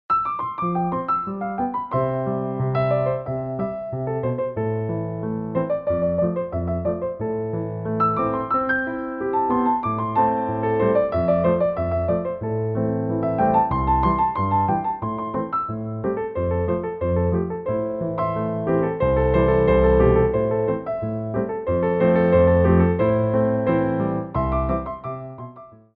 Petit Allegro 1
4/4 (16x8)